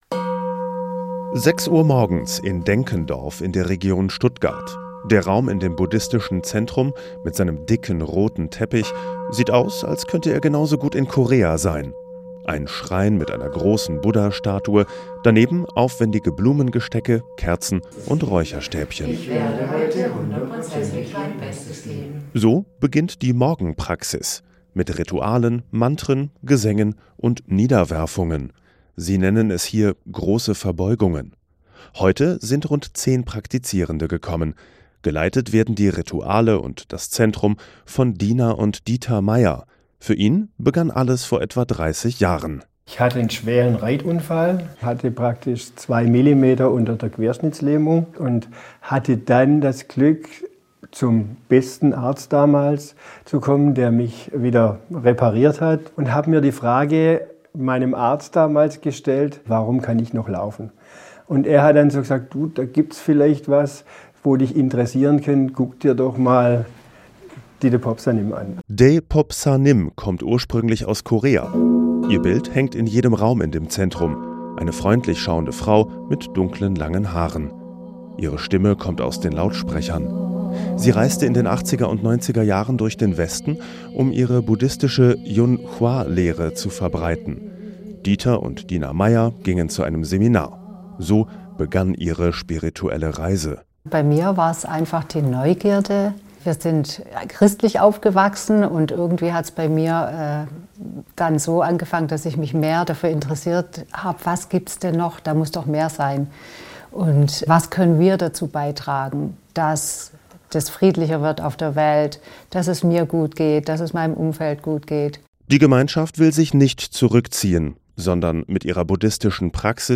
Rituale, Gesänge und Verbeugungen
Laut und kraftvoll schlägt sie das bauchige Holzinstrument, erst kurz, dann öfter und auslaufend: "Tok, tok, tok, tok, toktoktoktoktok".
Es folgen Verbeugungen, ein ständig wiederholtes Mantra und andere Gesänge.